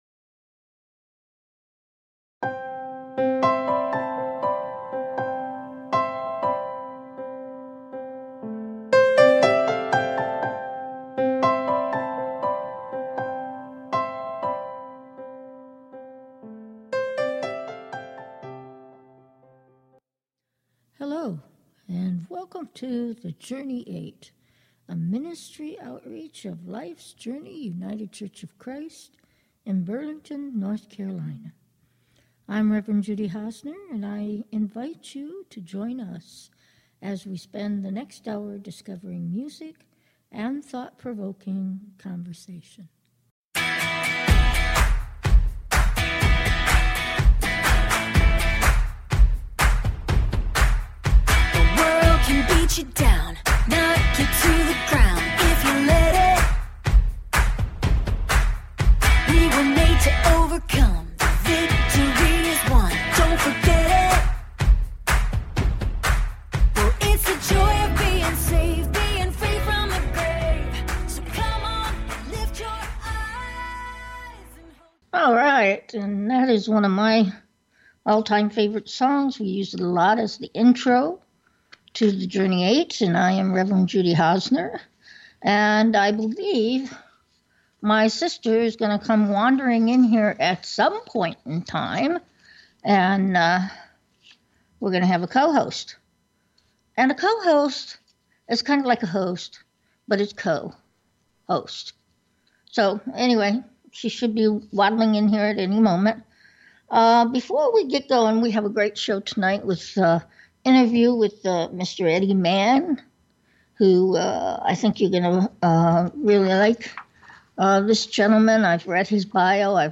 Talk Show Episode
The Journey 8 is a Christian based talk show that crosses the barriers of fear and hatred to find understanding and tolerance for all of God’s children.